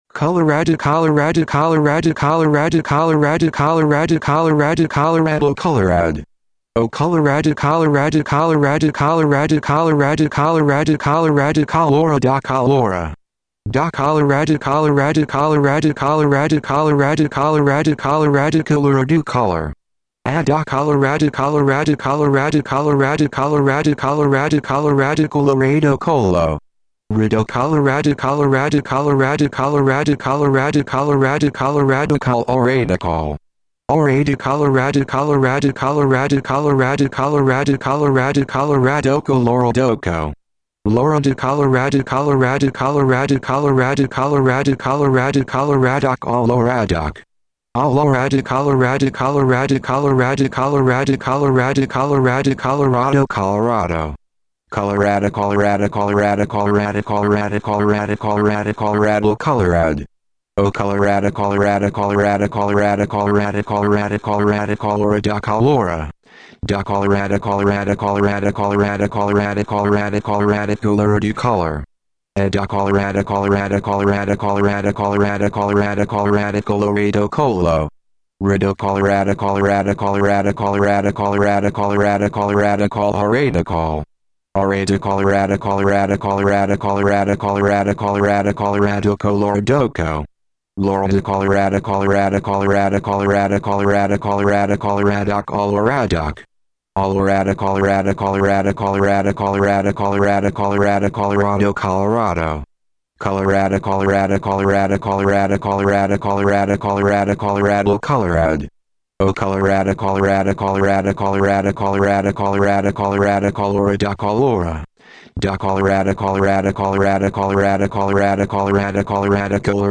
Dramatic reading